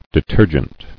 [de·ter·gent]